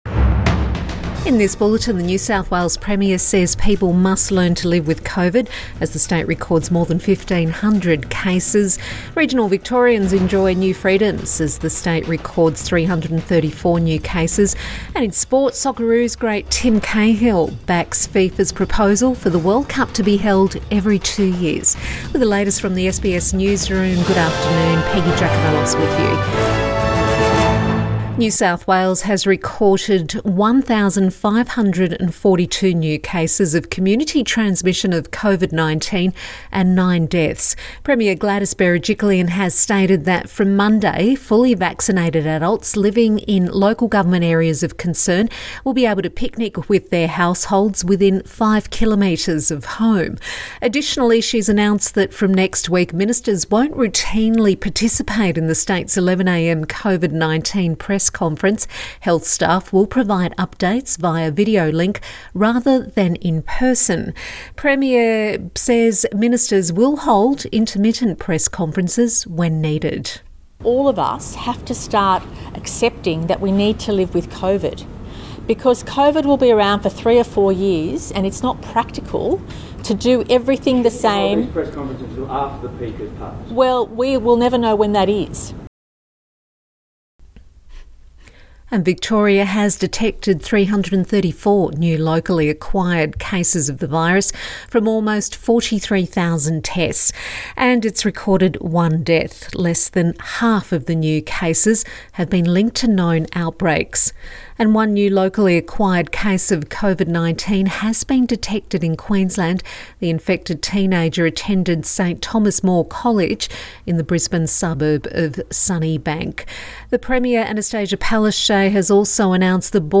Midday bulletin 10 September 2021